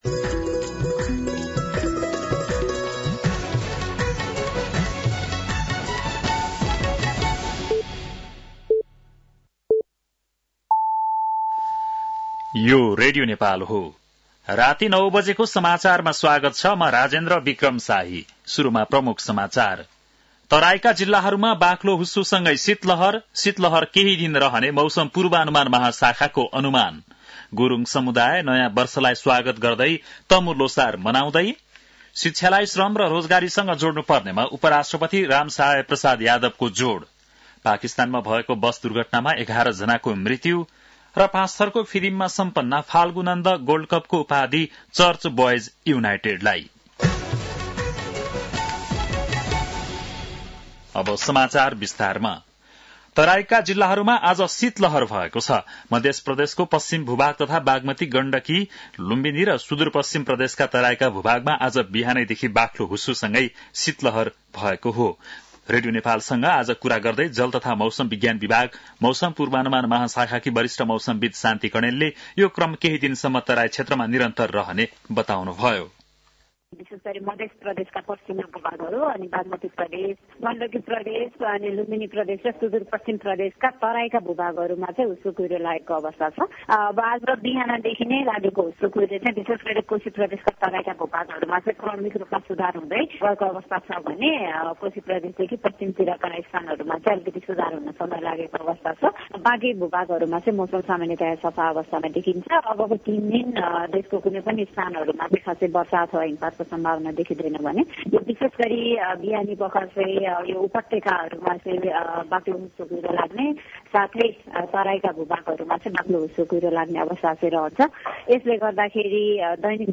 बेलुकी ९ बजेको नेपाली समाचार : १६ पुष , २०८१
9-PM-Nepali-NEWS-9-15.mp3